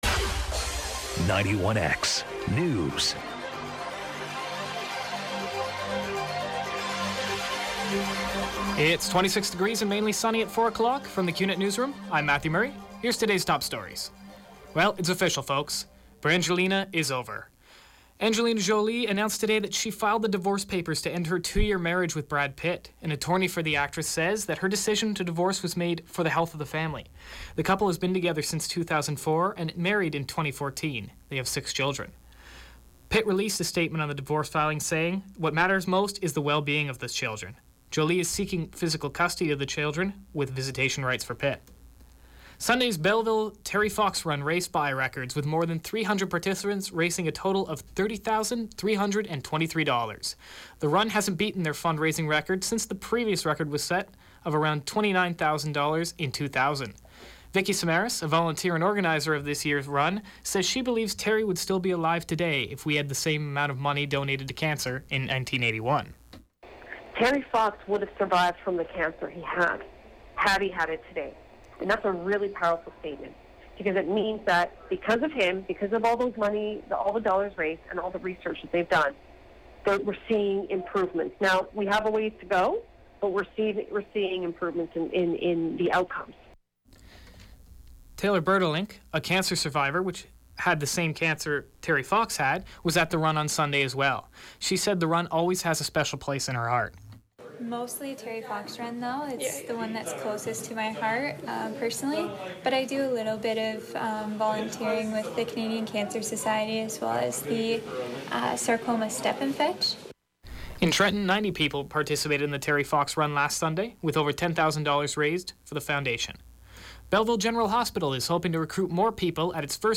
91X Newscast – Tuesday, September 20th, 2016, 4 p.m.